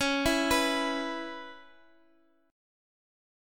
E-5th-Csharp-x,x,x,6,5,7-8.m4a